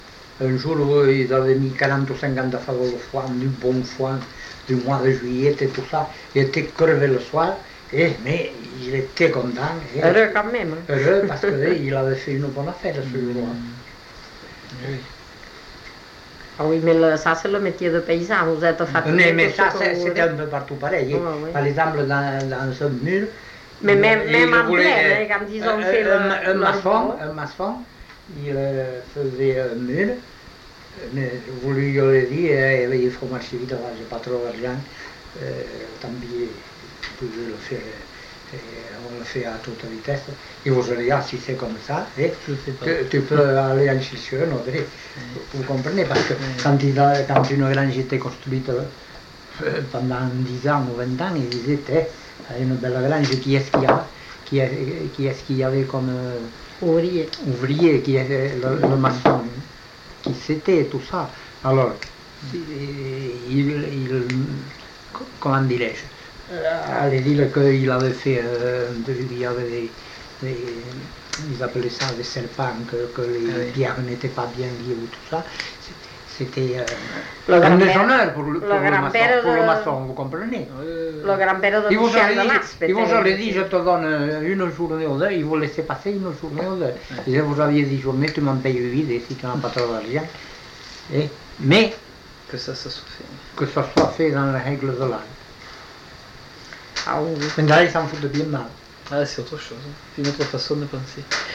Aire culturelle : Couserans
Lieu : Eylie (lieu-dit)
Genre : témoignage thématique